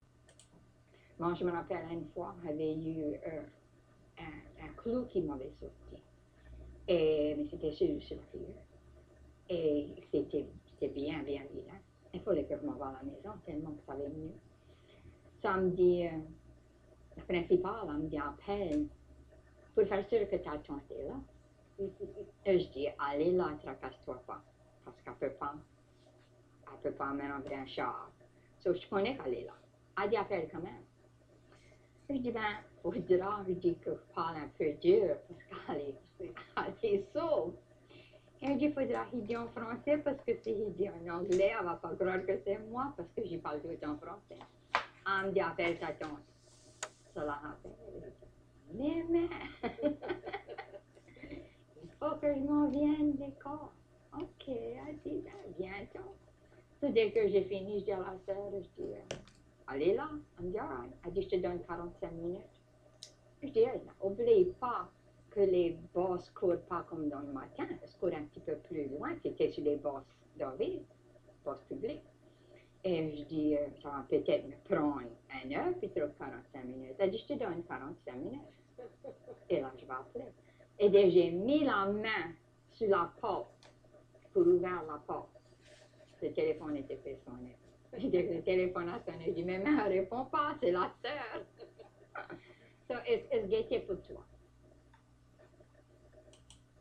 Lafourche - copia.m4a